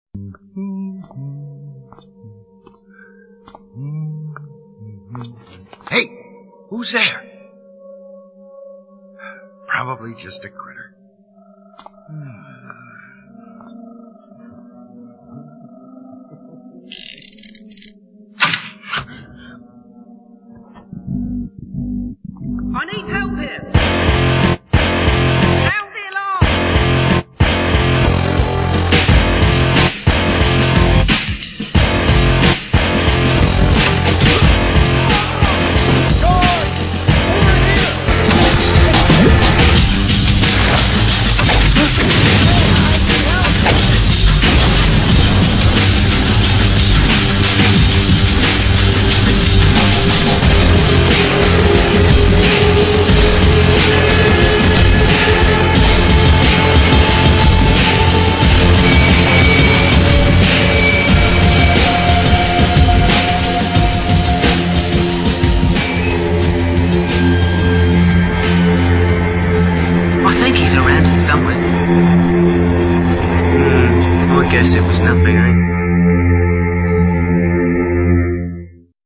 (Recorded from the Trailer)